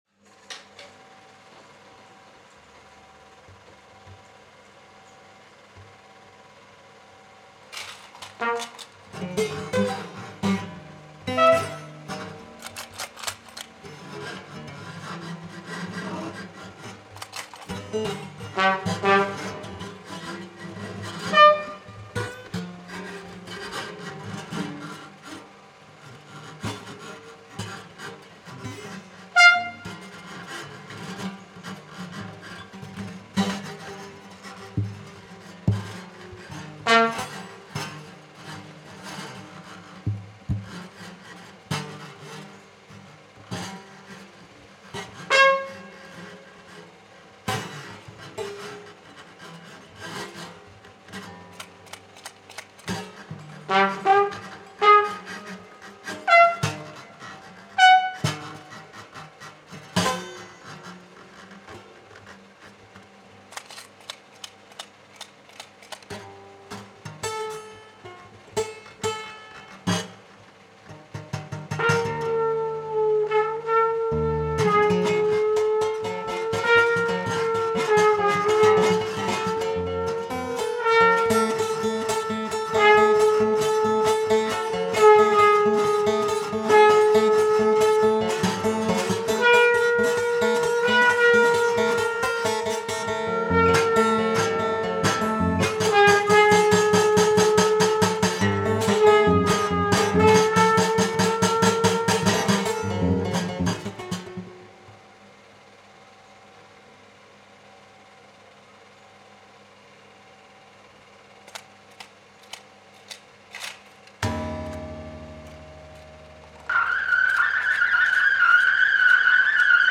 LIVE - july 2022 Huset Biograf, Copenhagen REEL 1 - audio REEL 2 - audio REEL 3 - audio REEL 1 - w. super-8 REEL 2 - w. super-8 REEL 3 - w. super-8